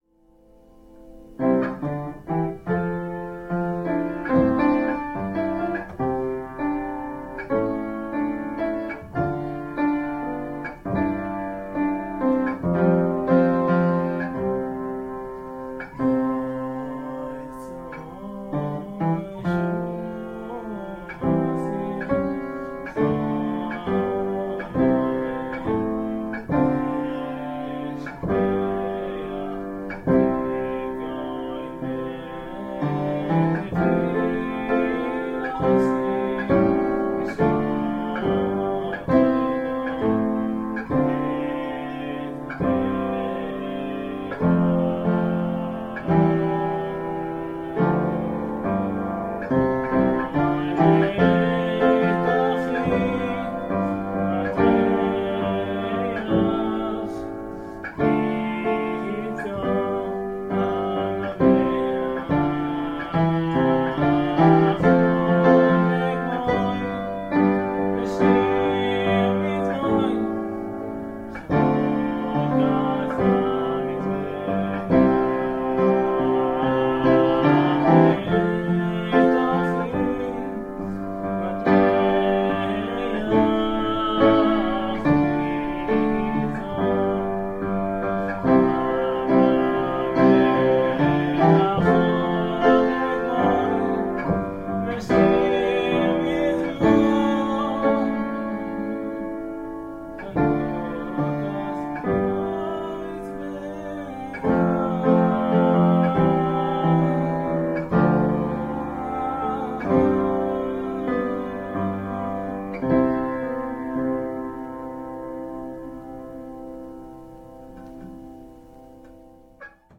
אני לא יכולה לישמוע אותך…רק שומעת פסנתר …סליחה…
האמת שהתלבטתי הרבה אם להעלות כאן את זה או לא, מפני שמדובר בהקלטה ממש פשוטה ופסנתר עץ ישן וחורק…